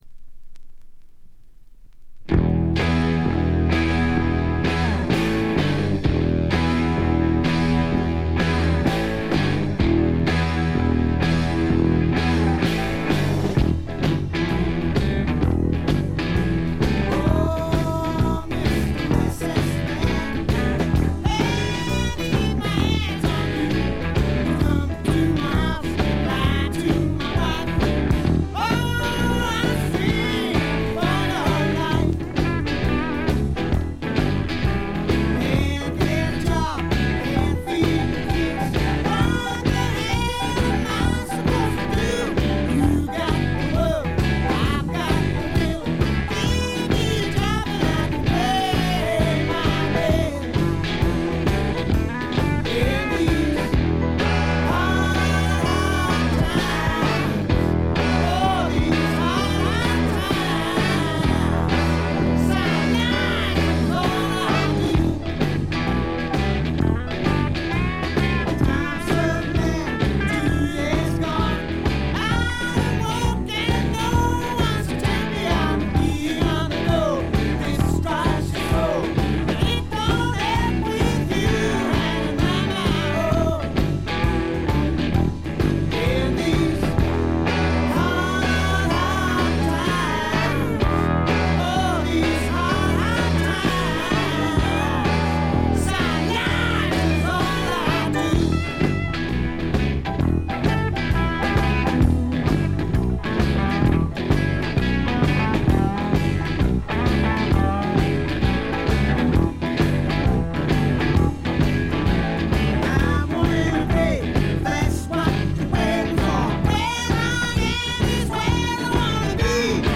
部分試聴ですが、軽微なチリプチ、バックグラウンドノイズ程度。
初期のマッギネス・フリントのようなスワンプ路線もありますが、それに加えて激渋ポップ感覚の漂うフォークロック作品です。
それにしてもこの人の引きずるように伸びのあるヴォーカルは素晴らしいでね。
試聴曲は現品からの取り込み音源です。